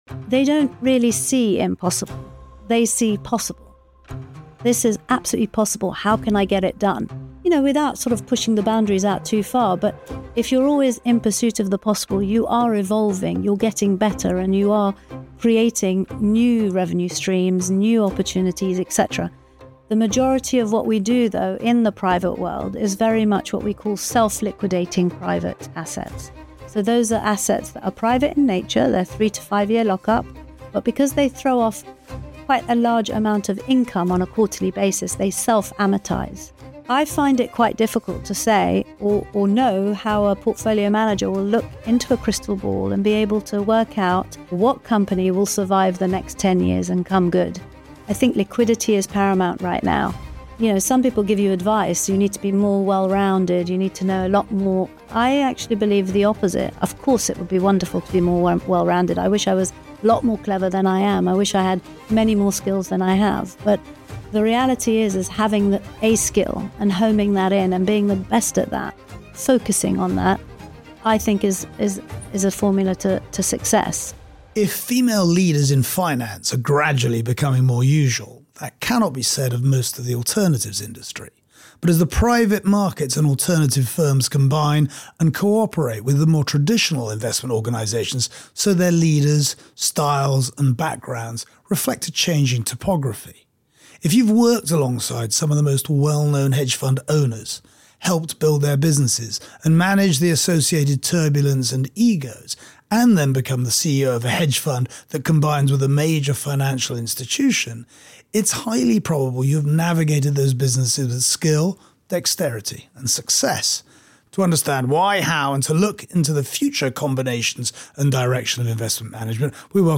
In this episode we explore a slightly different realm: religion. Our guest, the Reverend Nicky Gumbel, talks of his own odyssey from barrister to clergyman, before widening both awareness and access to Christianity to those outside the church via the Alpha Course.